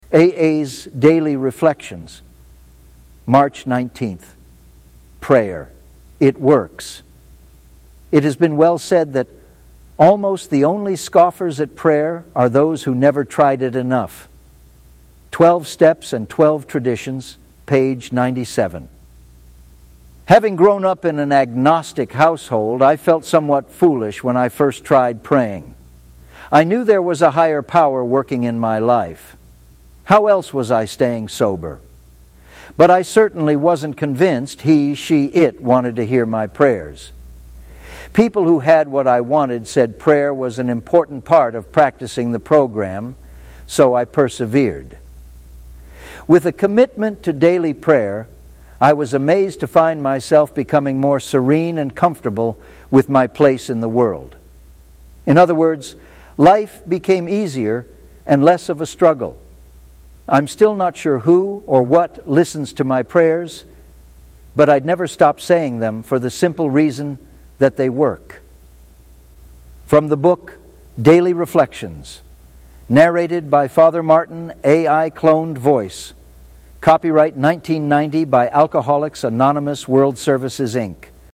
A.I. Cloned Voice